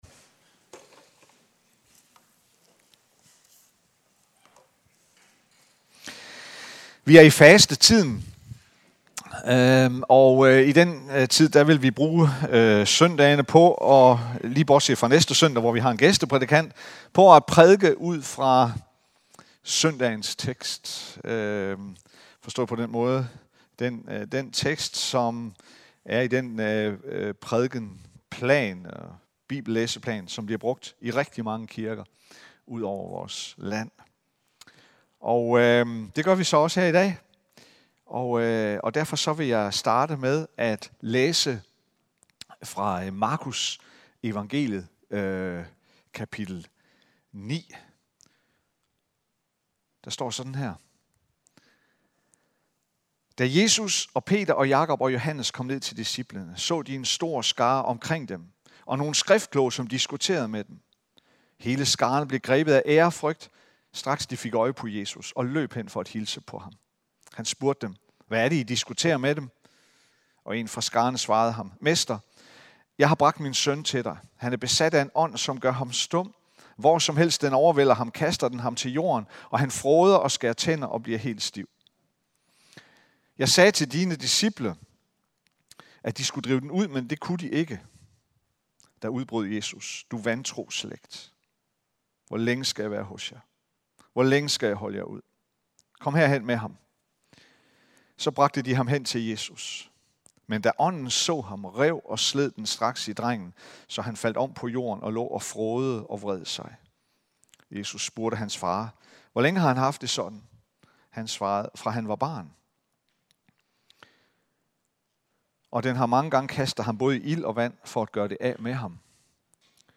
Prædiken 1/3 2026